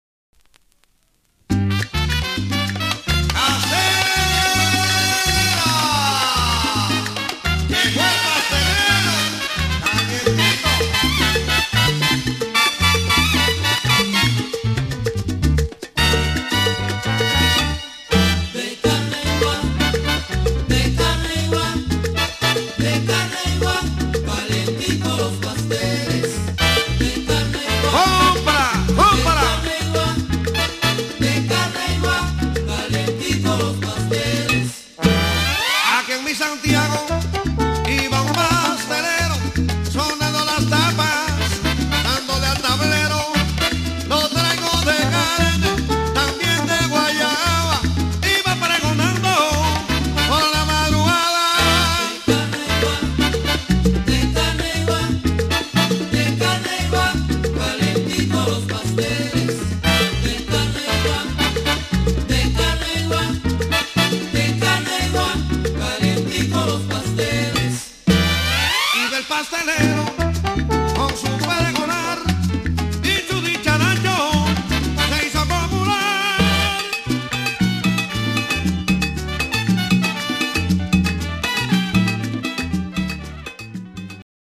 伝統的なキューバン・ソンを現代的な手法で新しく、ダンサブルにし、人気を博した。